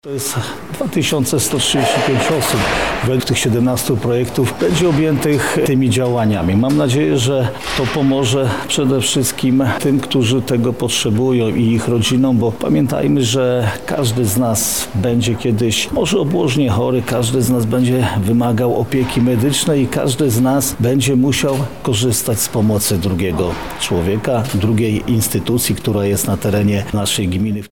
Jarosław Stawiarski– mówi Jarosław Stawiarski, marszałek województwa lubelskiego.